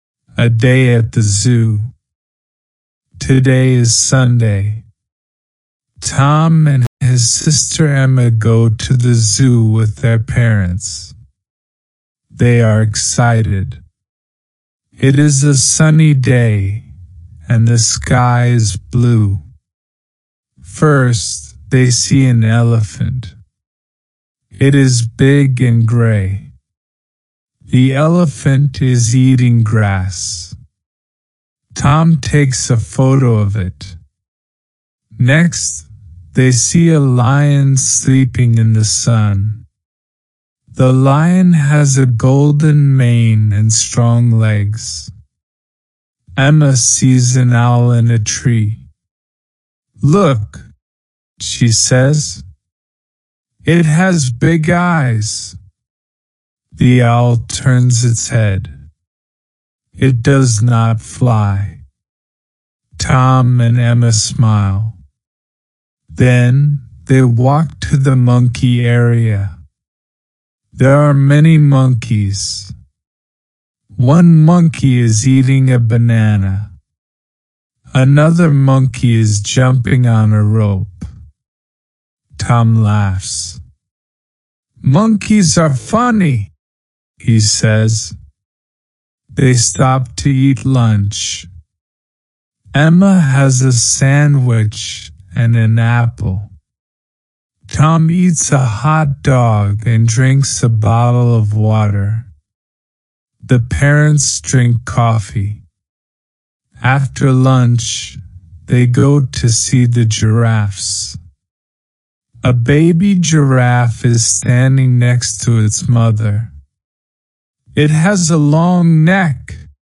LISTENING PRACTICE
Click on the left picture below to listen to the slow audio version, or on the right picture for the normal-speed version.
EGA102-A-day-at-the-zoo-SLOW.mp3